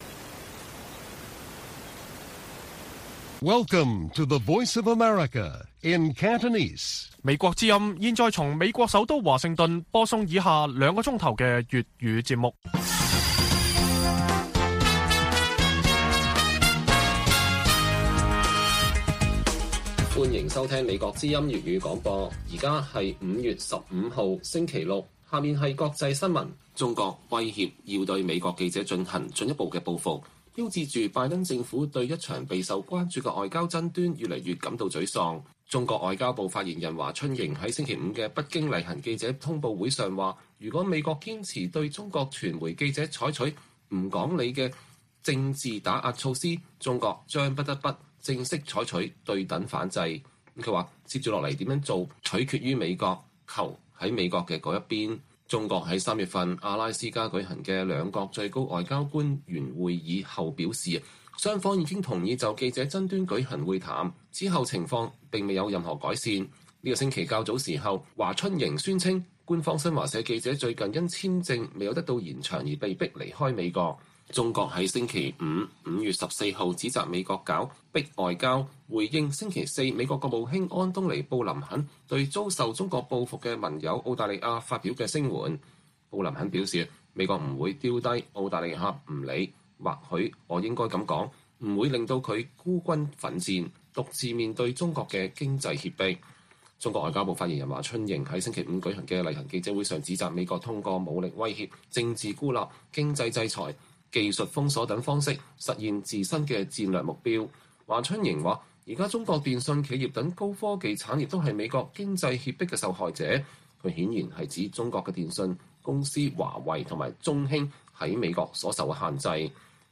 粵語新聞 晚上9-10點
北京時間每晚9－10點 (1300-1400 UTC)粵語廣播節目。內容包括國際新聞、時事經緯和英語教學。